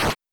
receivedamage.wav